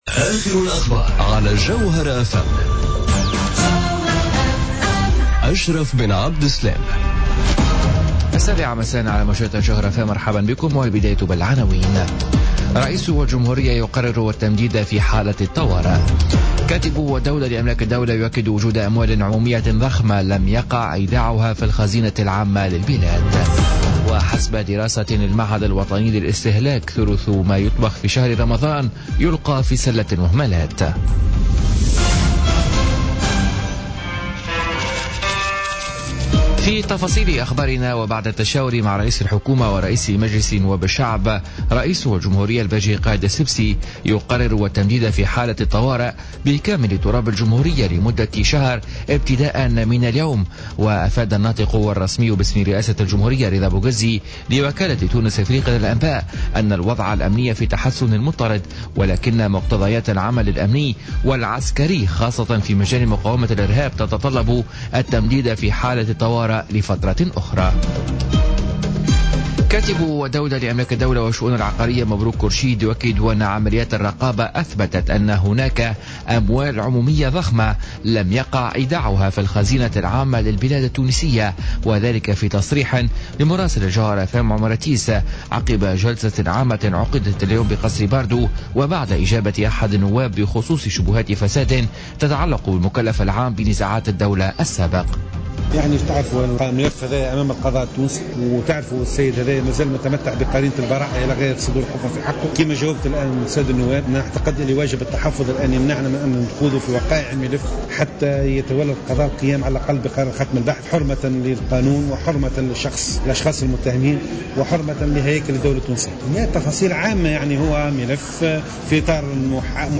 نشرة أخبار السابعة مساء ليوم الثلاثاء 16 ماي 2017